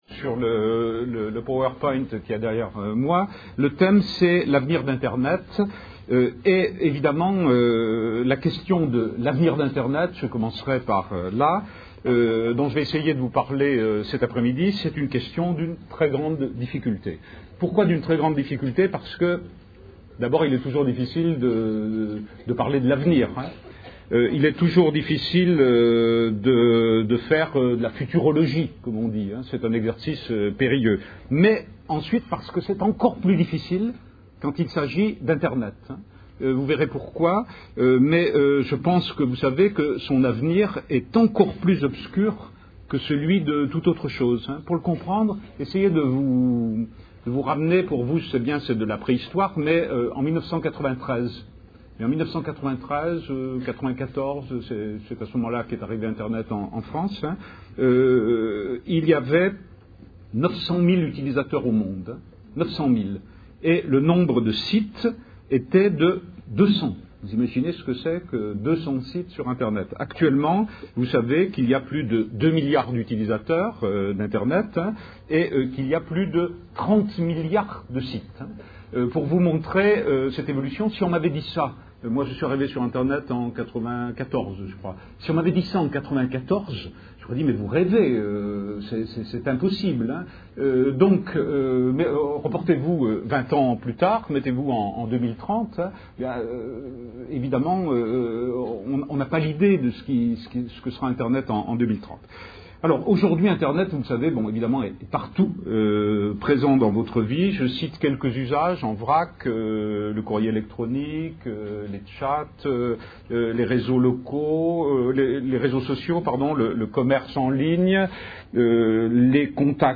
Une conférence de l'UTLS au Lycée